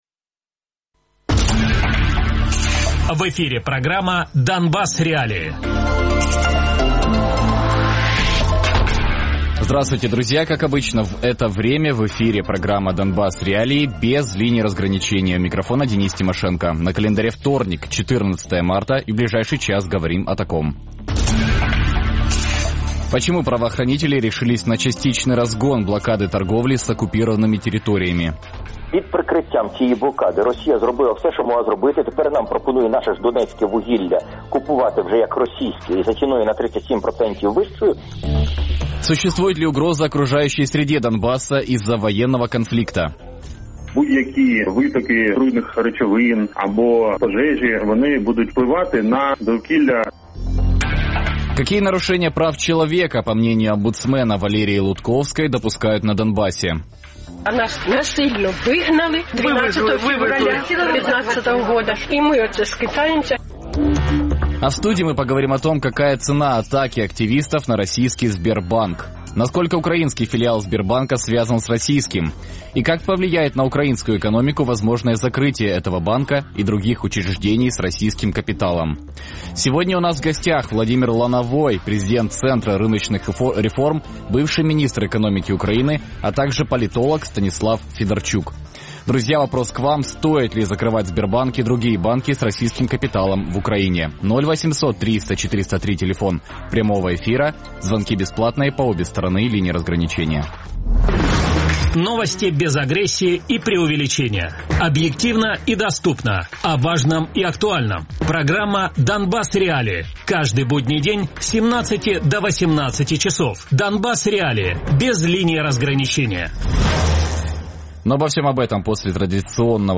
политолог Радіопрограма «Донбас.Реалії» - у будні з 17:00 до 18:00.